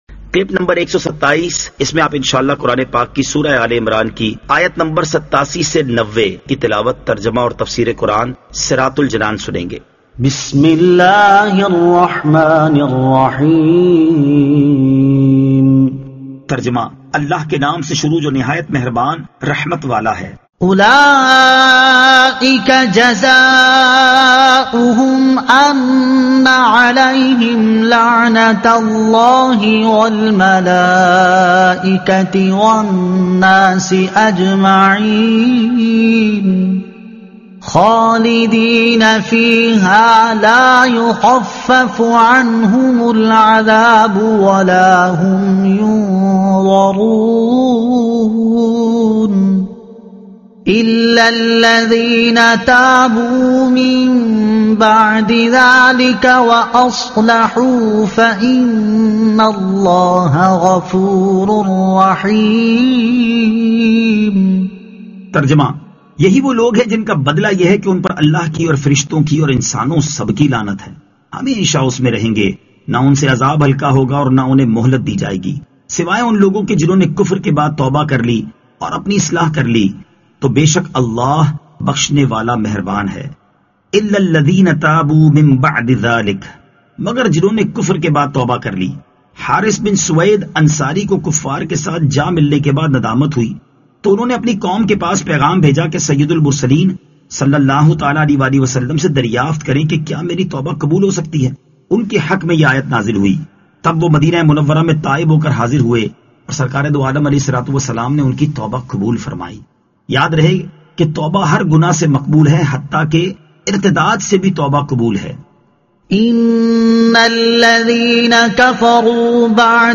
Surah Aal-e-Imran Ayat 87 To 90 Tilawat , Tarjuma , Tafseer